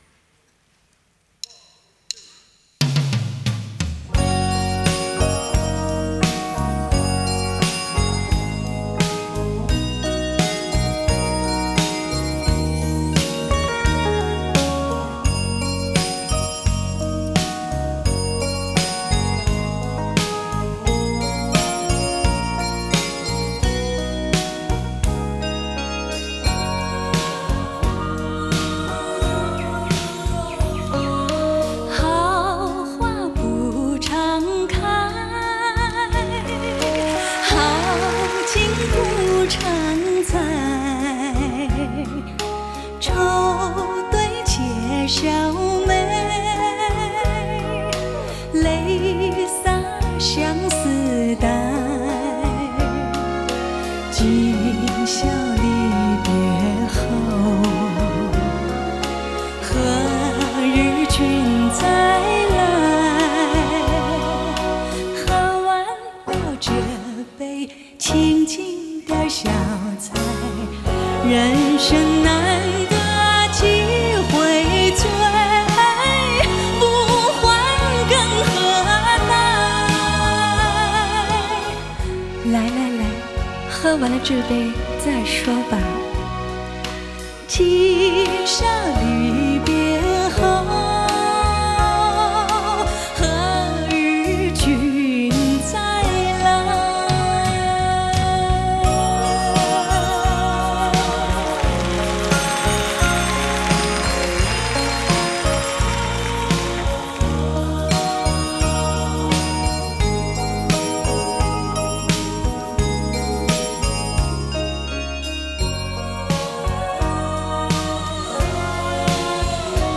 ジャンル: Japanese Pop